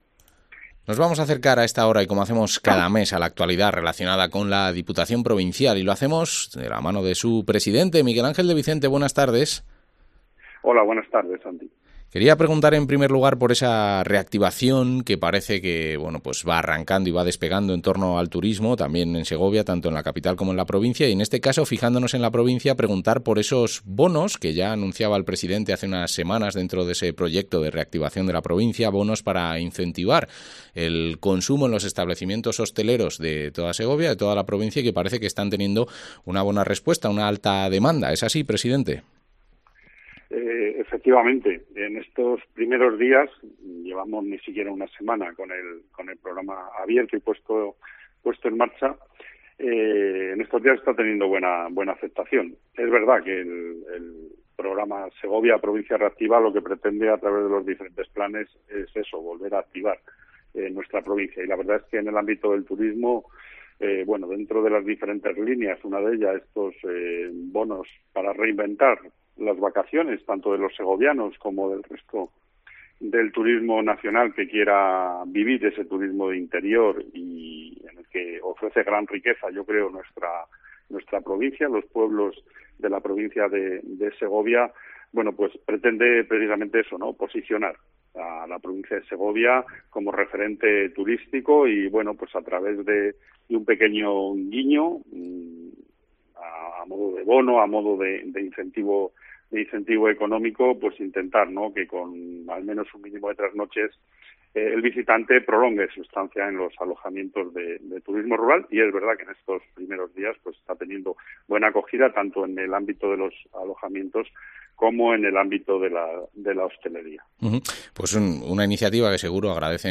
Entrevista al presidente de la Diputación de Segovia, Miguel Ángel de Vicente